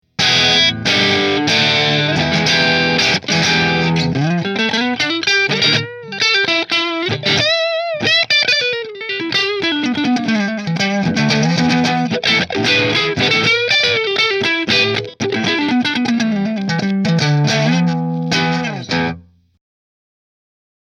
This bolt-on neck semihollow guitar is a tone machine!